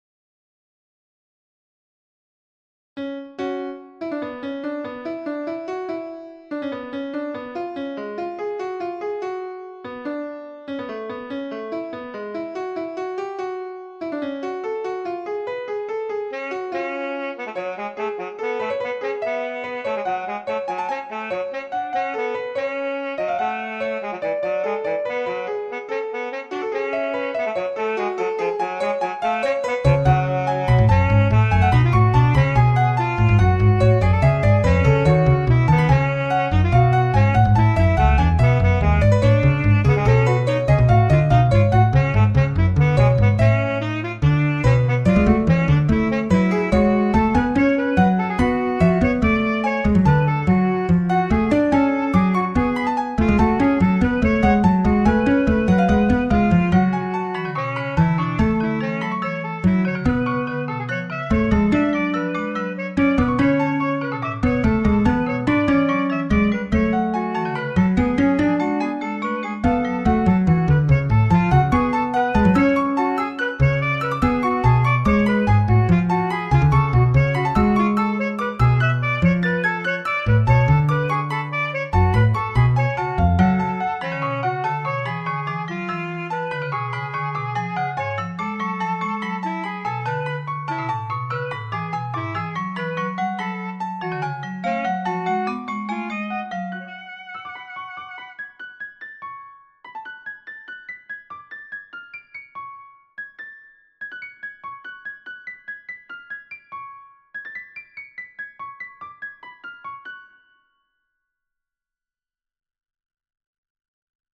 Fugue
Hi all, This is a fugue i have written some years ago with midi orchestrator +. It is not finished, it needs some tuning and corrections.